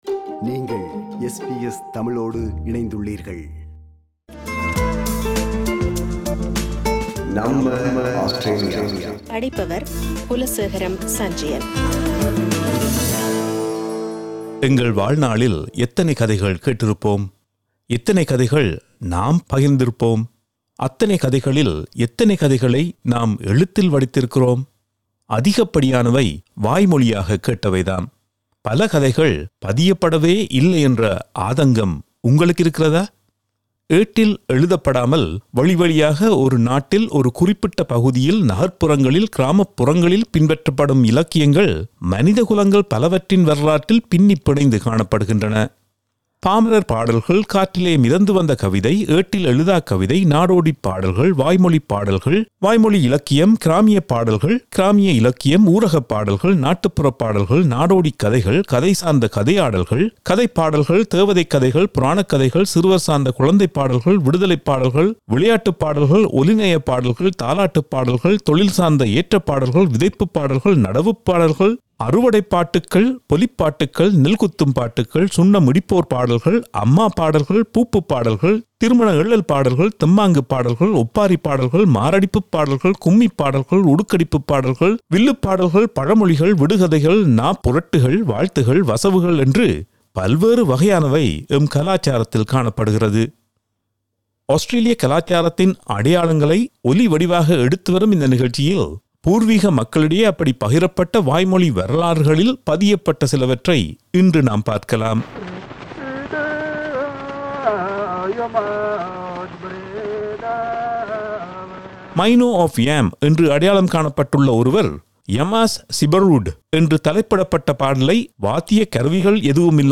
ஆஸ்திரேலிய இசைப்பரப்பில் பூர்வீக மக்கள் இசை